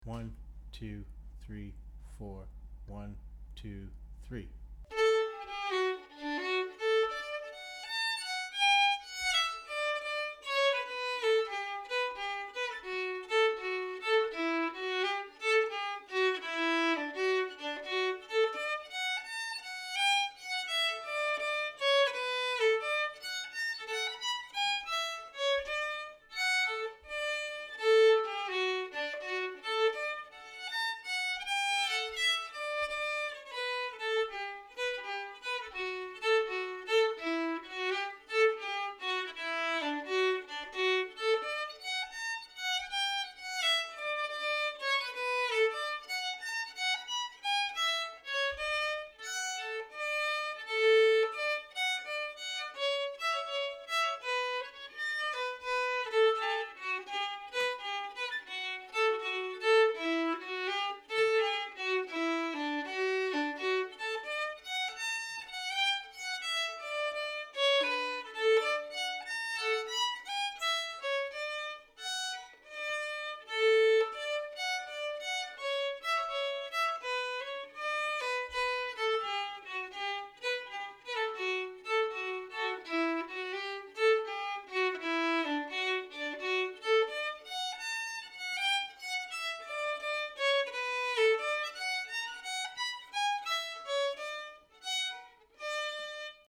Key:D
Form:Hornpipe
Region: British Isles